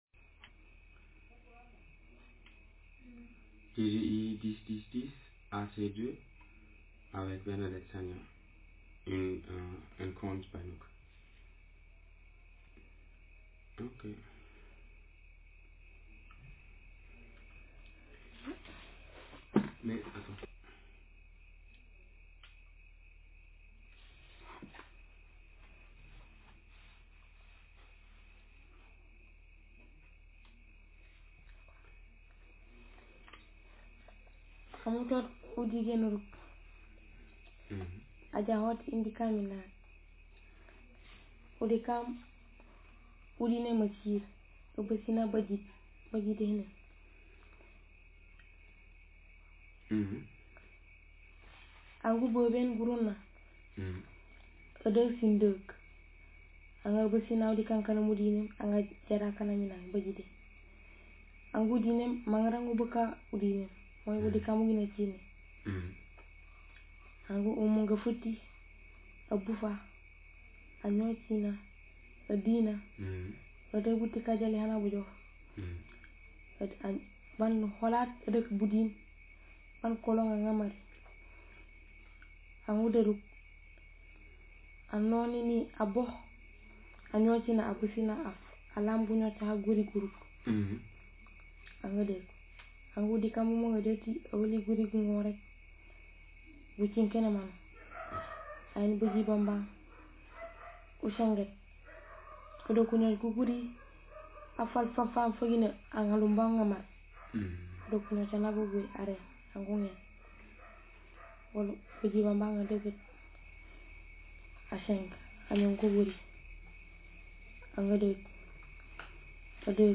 Speaker sex f Text genre traditional narrative